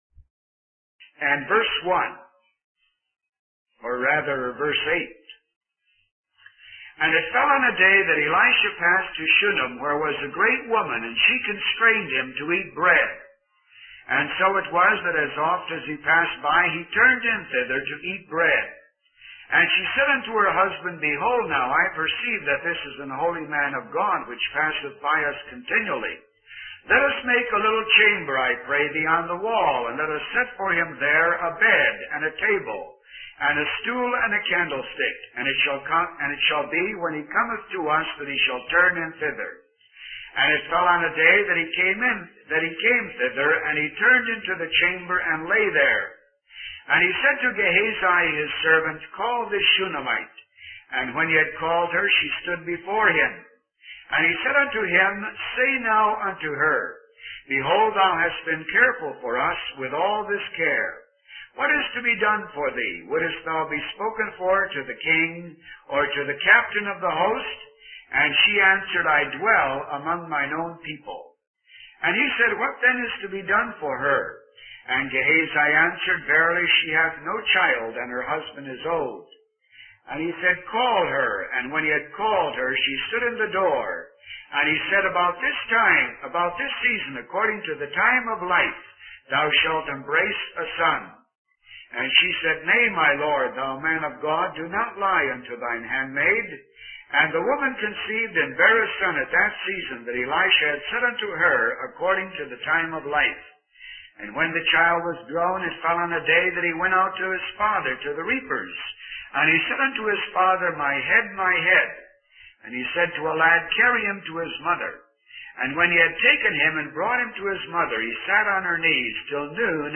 In this sermon, the speaker reflects on the importance of trials and afflictions in drawing us closer to the Lord.